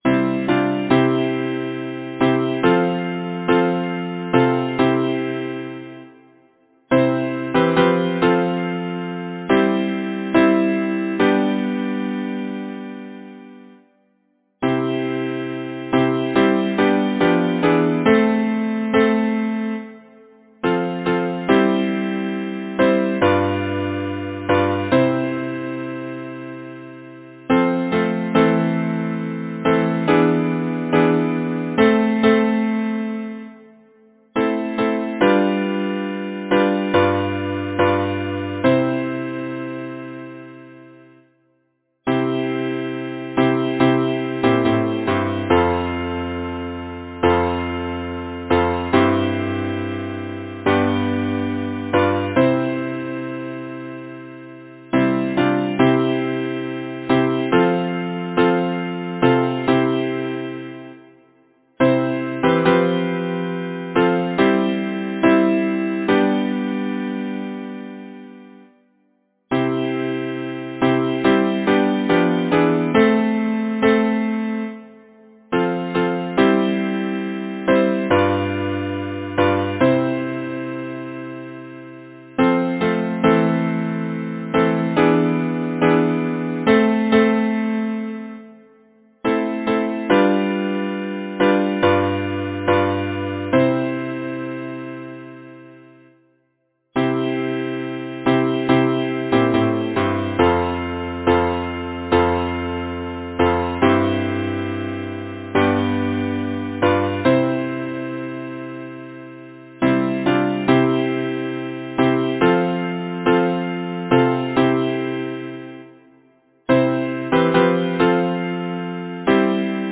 Title: Twelve O’clock Composer: Ossian E. Dodge Lyricist: Number of voices: 4vv Voicing: SATB Genre: Secular, Partsong
Language: English Instruments: A cappella